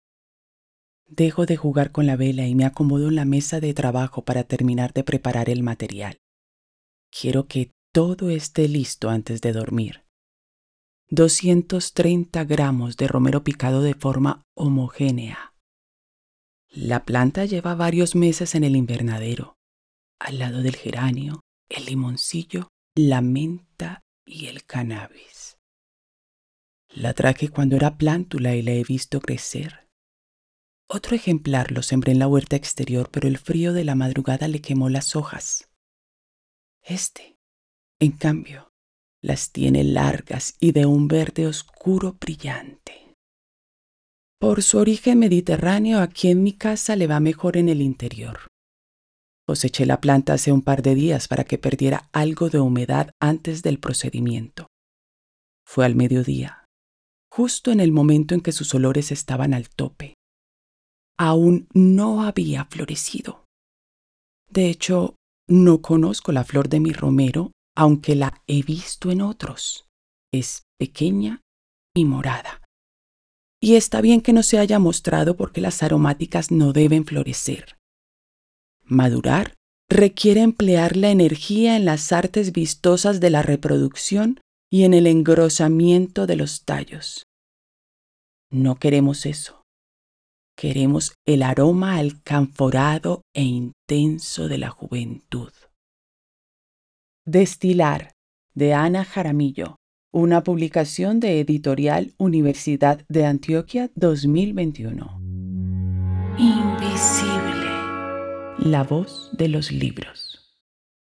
Fragmentos de audiolibros